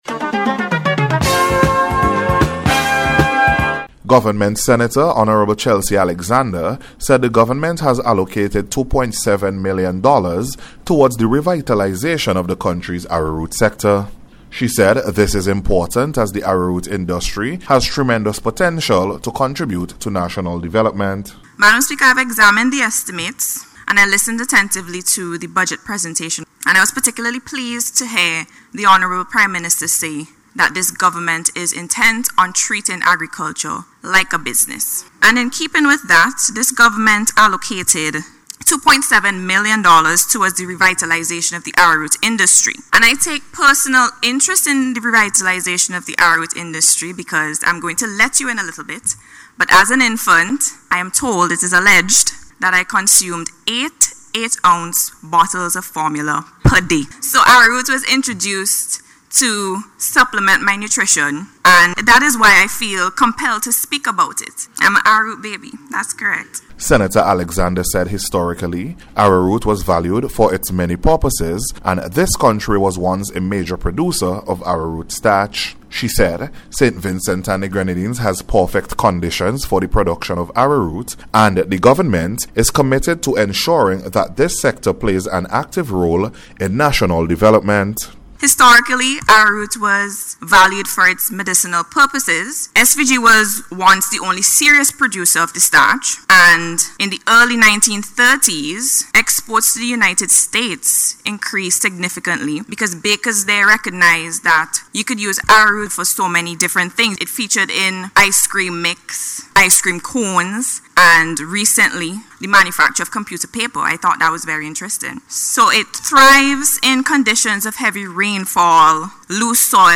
NATIONAL-ARROWROOT-REVILTALISATION-REPORT.mp3